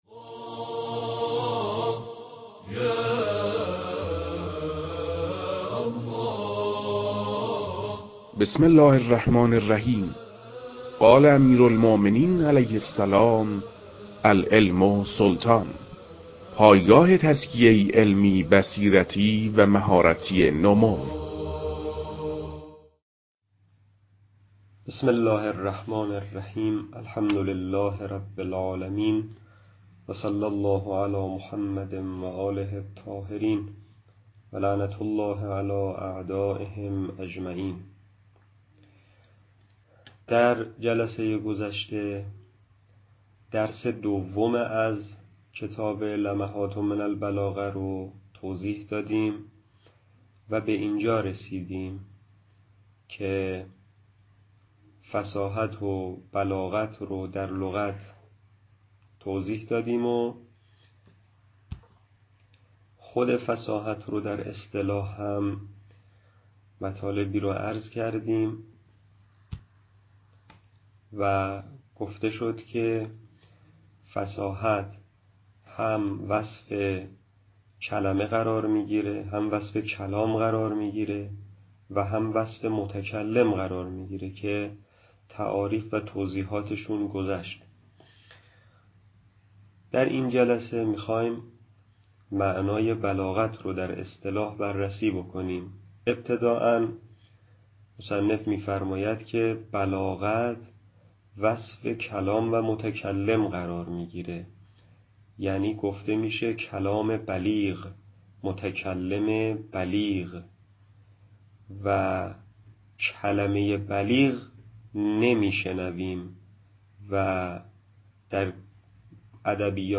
در این بخش، کتاب «لمحات من البلاغة» که اولین کتاب در مرحلۀ آشنایی با علم بلاغت است، به صورت ترتیب مباحث کتاب، تدریس می‌شود.